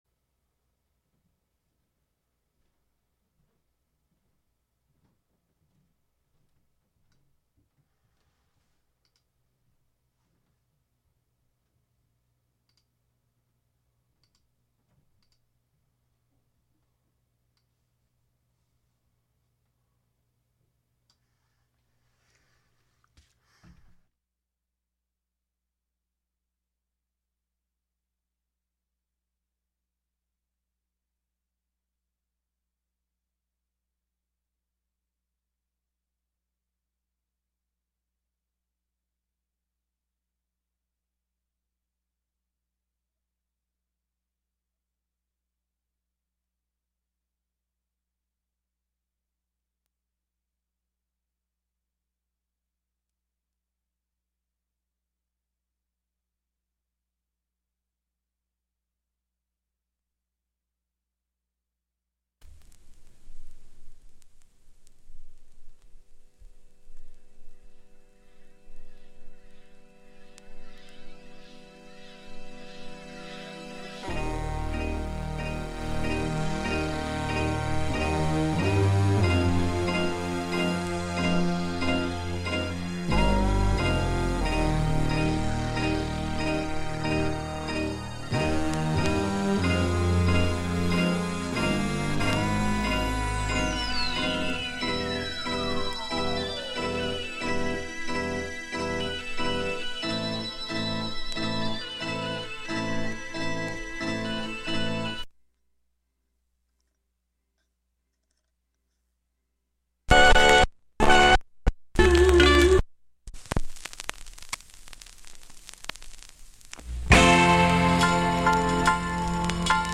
Like a mix-tape on your radio!